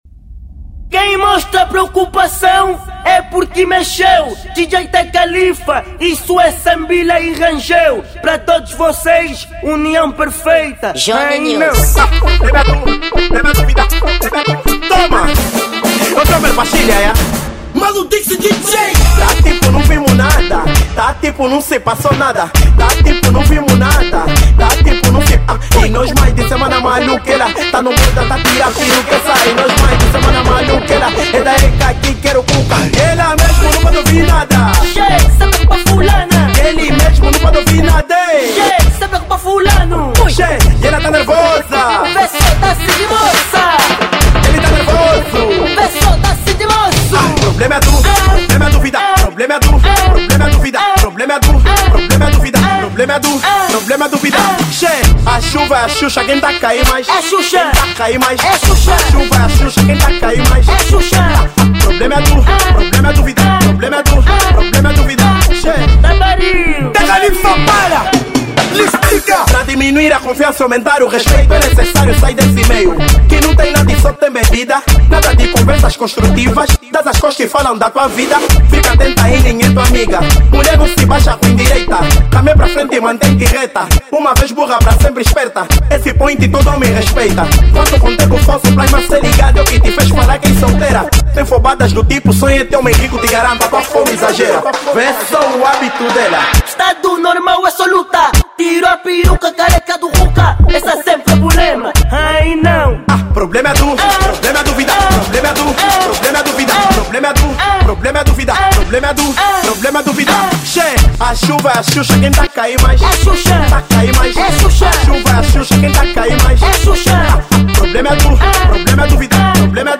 Gênero: Afro House